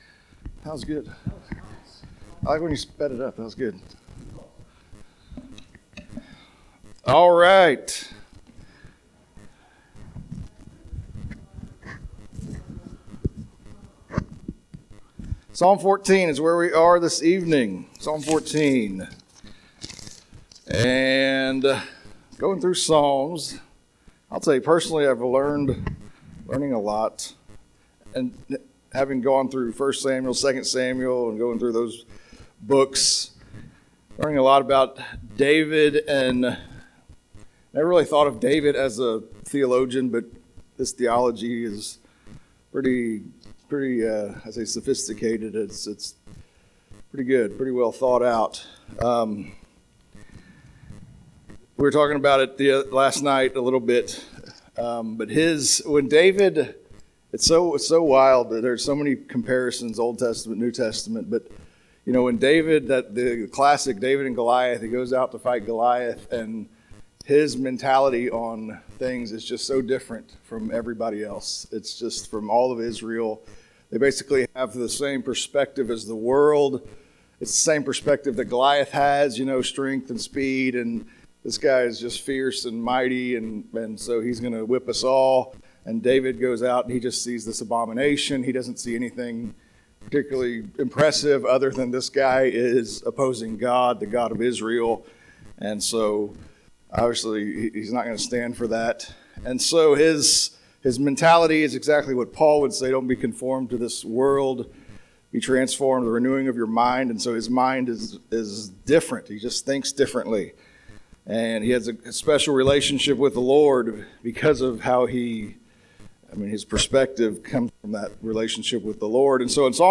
1 Episode 853: 04-21-24 Modern Worship Service, Audio 1:05:41
Modern Worship Praise Team (11:15 Service)